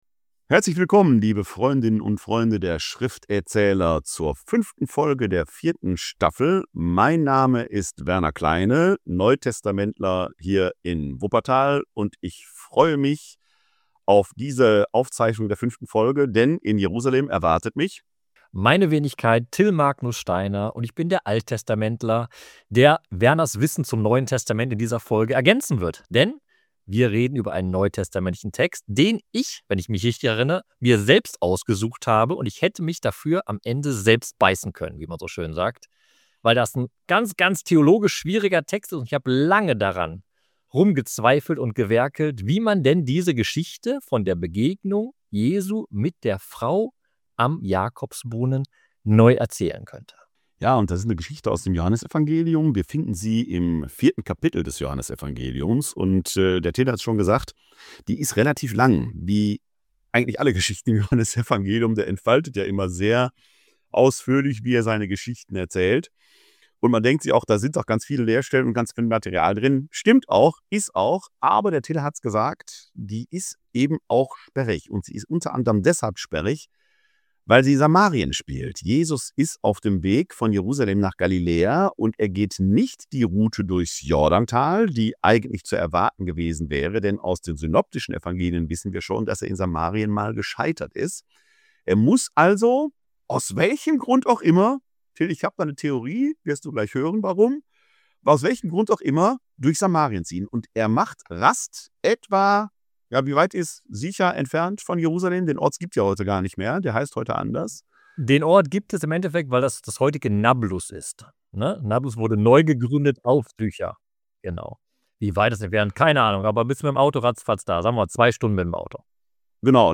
In dieser Folge erzählen sie diesen Bibeltext neu und eröffnen durch ihre Neuerzählungen ganz neue und erkenntnisreiche Perspektiven.